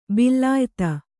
♪ billa